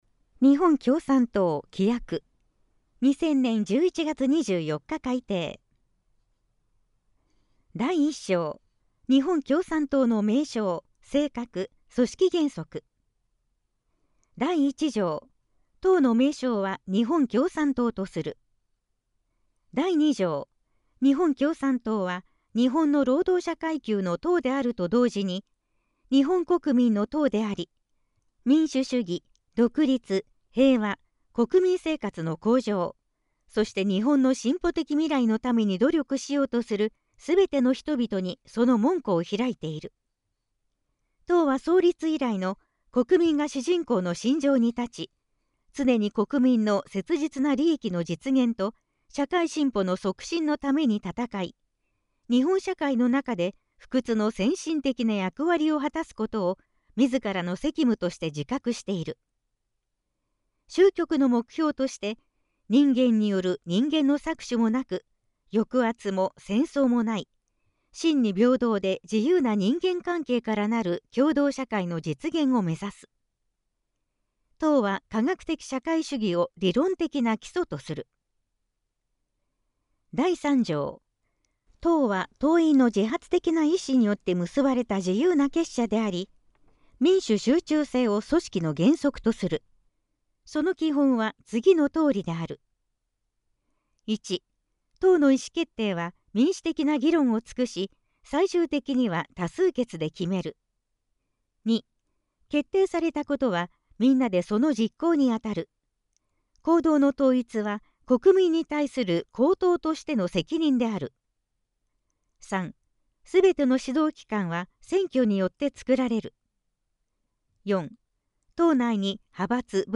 規約を朗読したものを、mp3形式の音声データと、デイジー形式のファイルにしました。